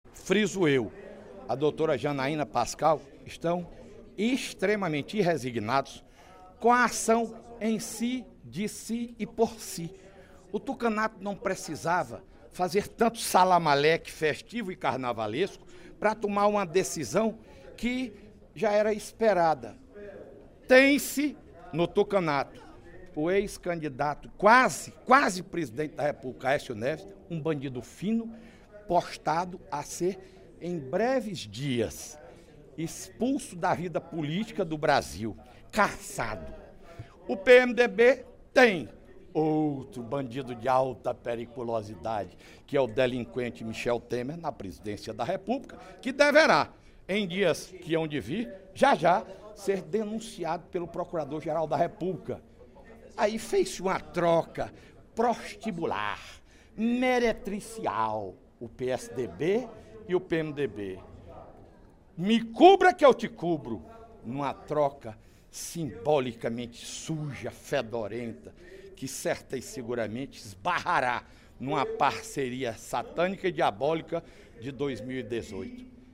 O deputado Fernando Hugo (PP) lamentou, durante o primeiro expediente da sessão plenária desta terça-feira (13/06), a decisão do Tribunal Superior Eleitoral (TSE) de absolver a chapa Dilma-Temer, vencedora das eleições em 2014.